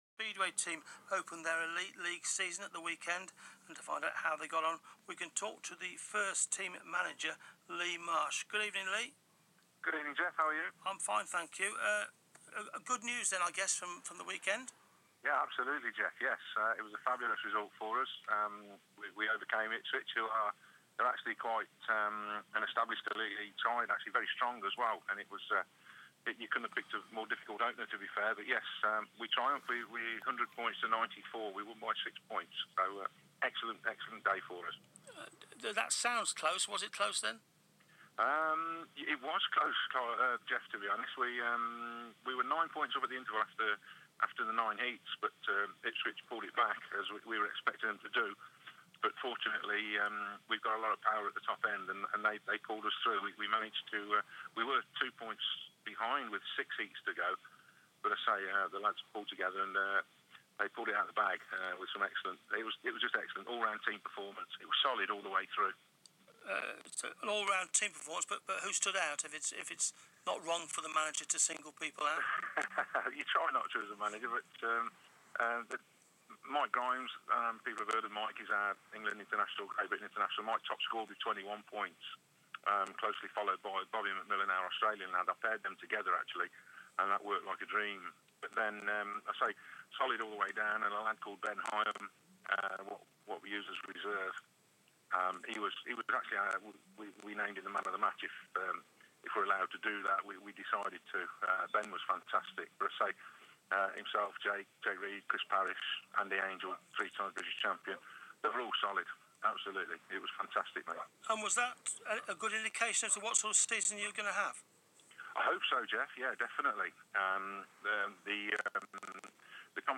BBC Coventry & Warwickshire Interview.